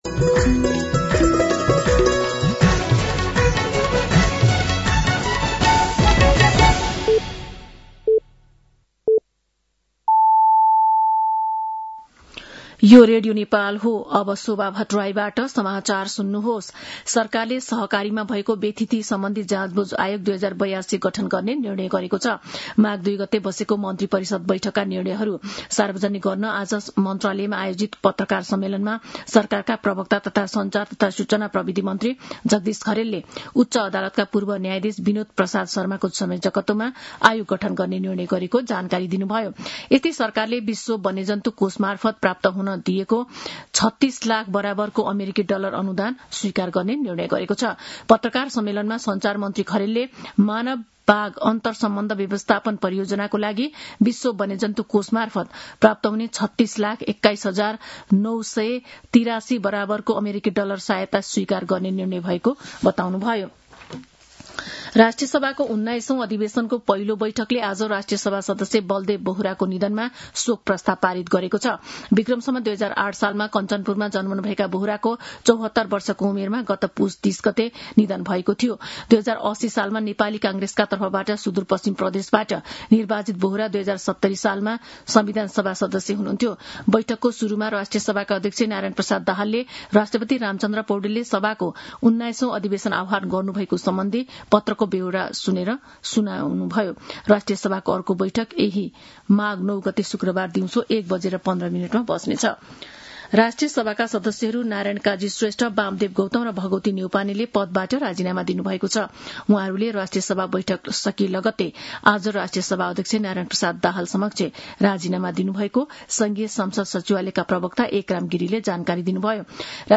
साँझ ५ बजेको नेपाली समाचार : ४ माघ , २०८२